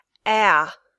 /æ/ and the sound /ə/ & /u:/ , /a:/, / ɔː/ ; linking.
ae.mp3